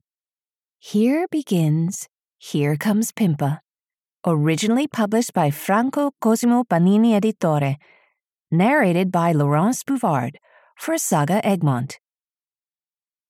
Here Comes Pimpa (EN) audiokniha
Ukázka z knihy